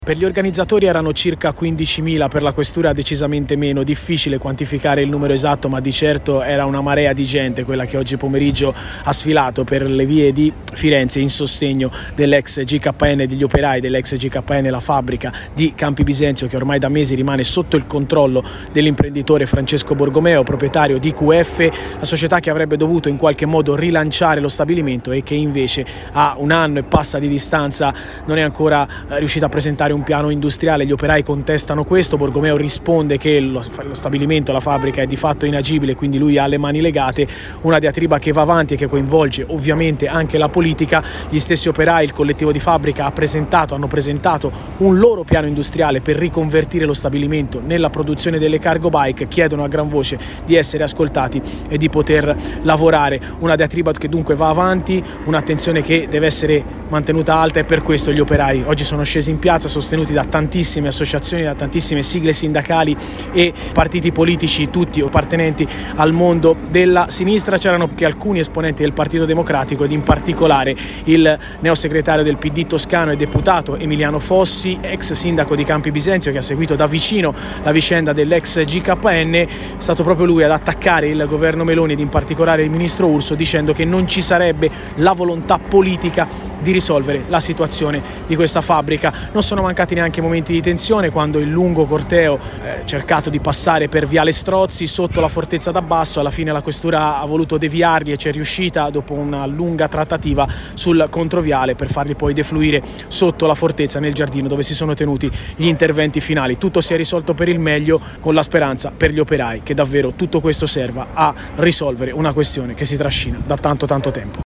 A Firenze nel pomeriggio migliaia di persone hanno partecipato alla manifestazione convocata dal collettivo di fabbrica della ex Gkn di Campi Bisenzio.